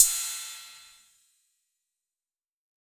6RIDE 2.wav